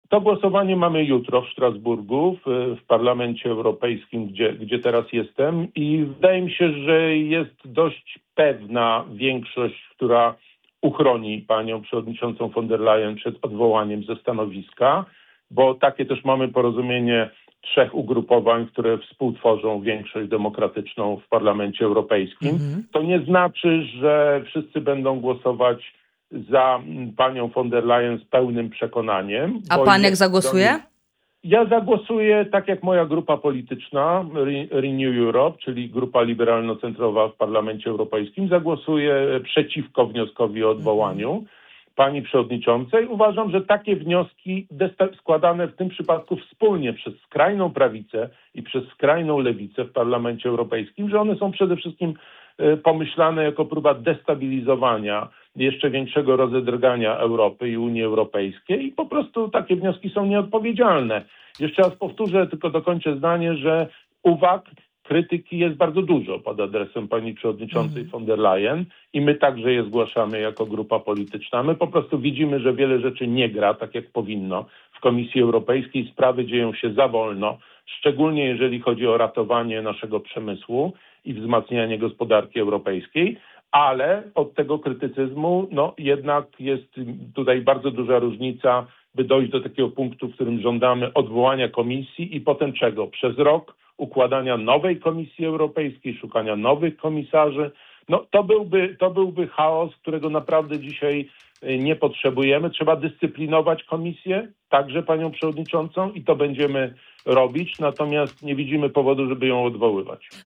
O sytuacji w partii, potencjalnych odejściach, ewentualnej tece wiceministra, a także głosowaniu nad odwołaniem przewodniczącej Komisji Europejskiej rozmawiamy z europosłem Krzysztofem Kobosko.